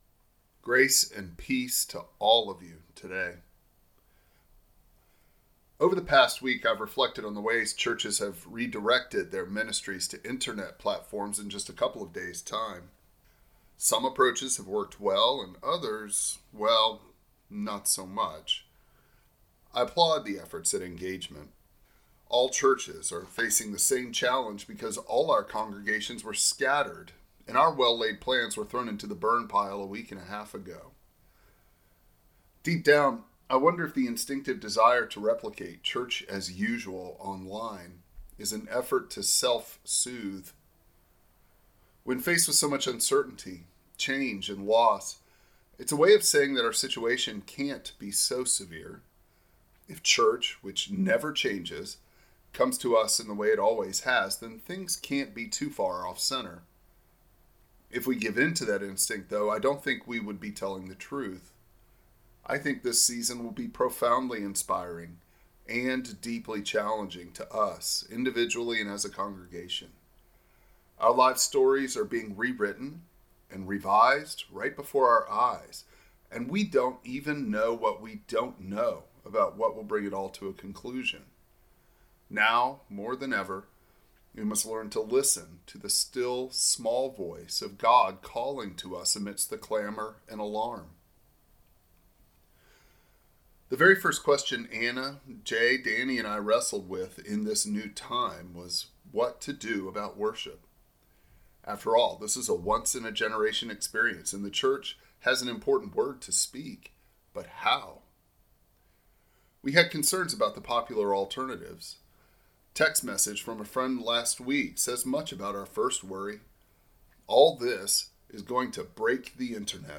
Passage: Psalm 62 Service Type: Traditional Service